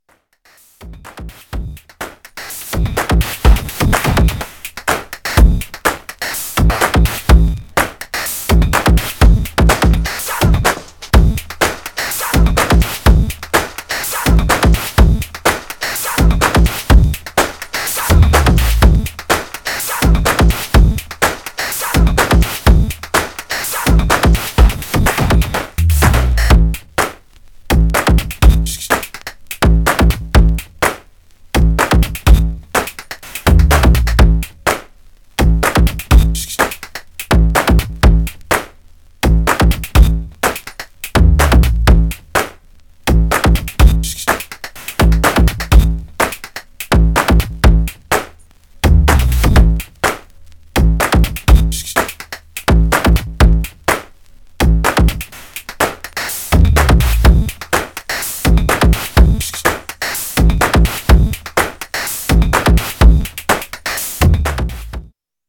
Styl: Hip Hop, House, Breaks/Breakbeat